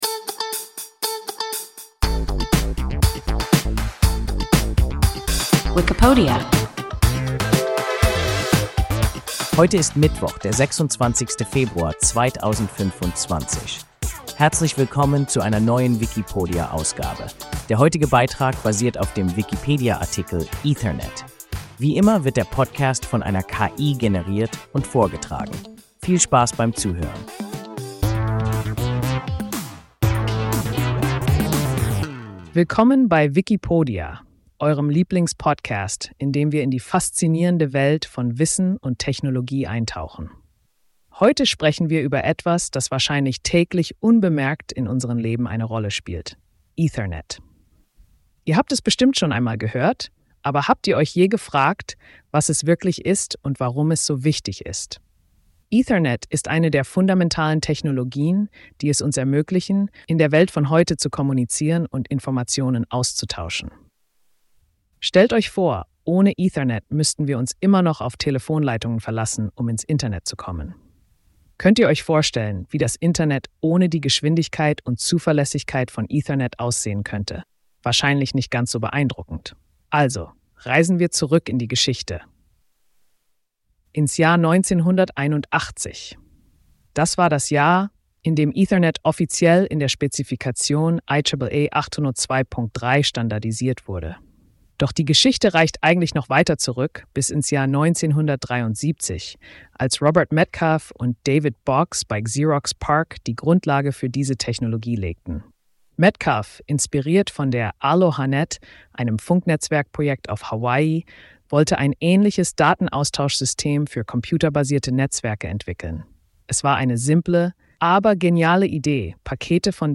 Ethernet – WIKIPODIA – ein KI Podcast